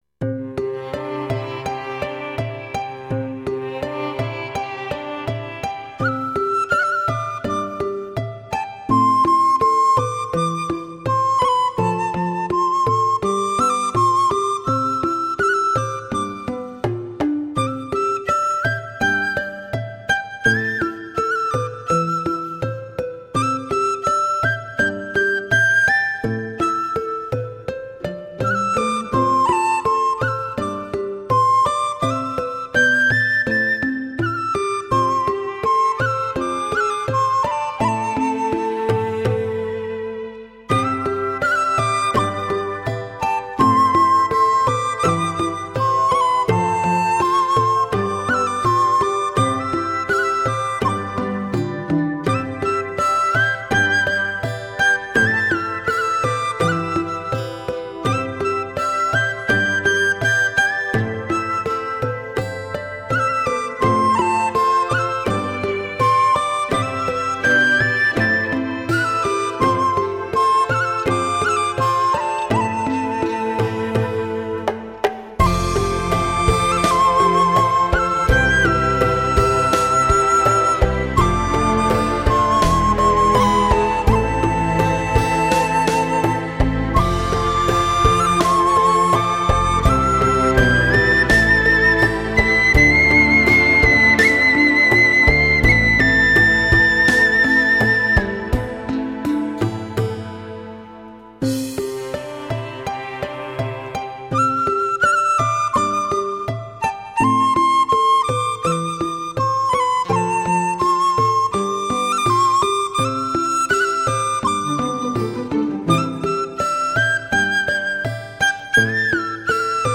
通过悠扬动听的竹笛独奏，演奏了14首辉煌灿烂的中国经典名曲。